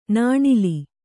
♪ nāṇili